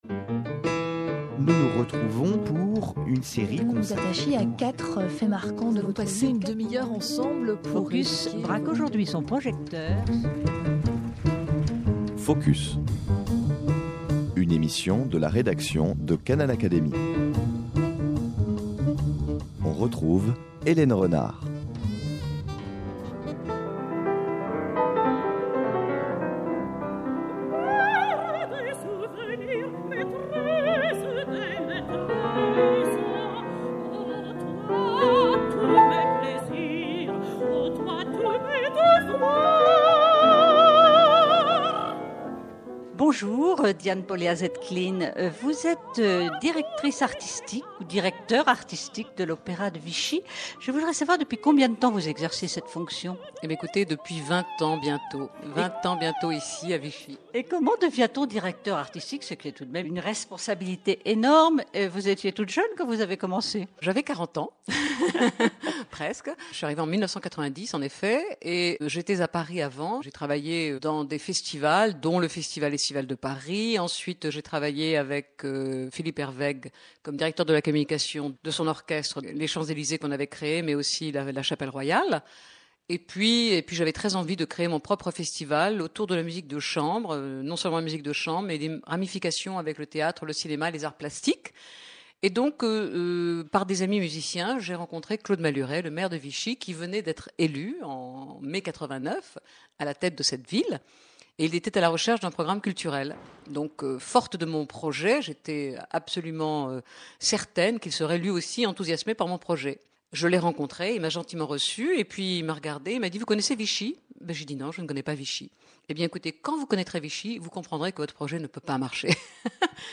Rencontre à l’opéra de Vichy